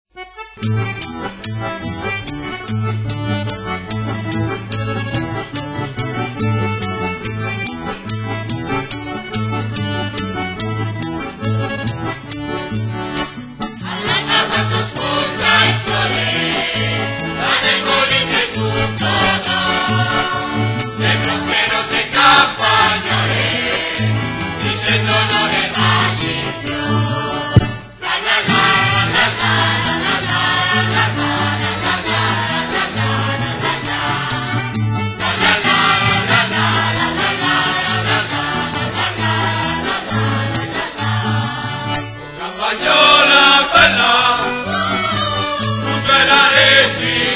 I canti antichi regionali, la rievocazione tramite balli di scene di vita agreste, di vecchi mestieri e di tradizioni popolari, caratterizzano le performances dei “Dragoni del Molise”.